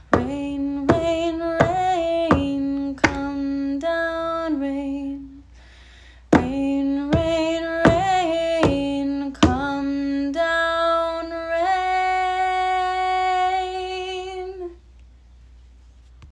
It’s very simple, with hand and arm movements.